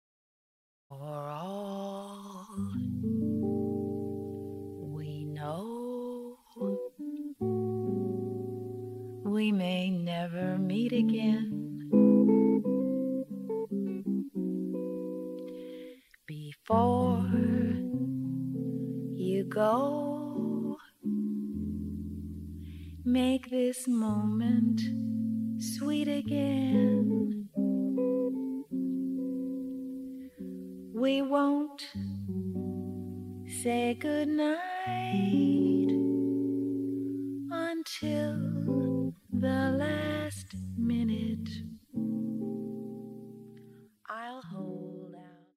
ヴォーカルは決して上手いとは言えない。でも寄り添うギターはその歌声に最大級の理解をもっている。
親密な空気感は親しい間柄だからこそでしょう。